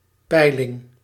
Ääntäminen
US : IPA : [ˈbɛə.rɪŋ] UK : IPA : /ˈbɛə̯ɹɪŋ/ US : IPA : /ˈbɛɹɪŋ/